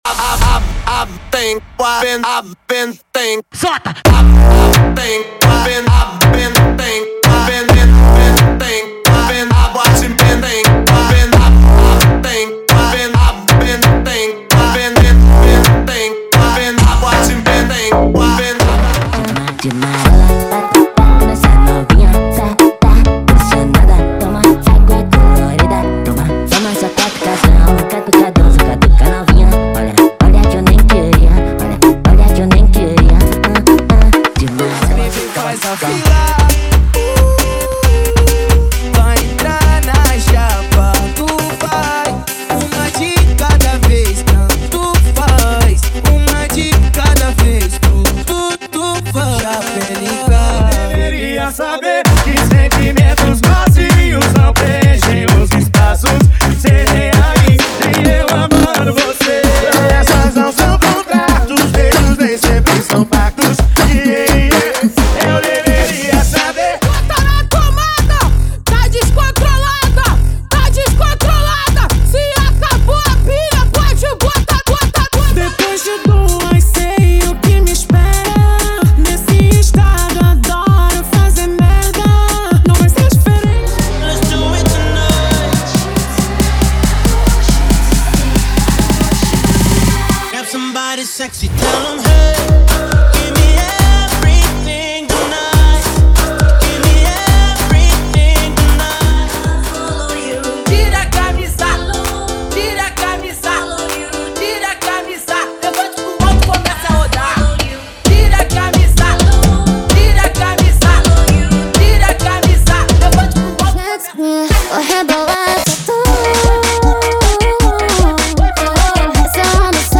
• Funk Light e Funk Remix = 100 Músicas
• Sem Vinhetas
• Em Alta Qualidade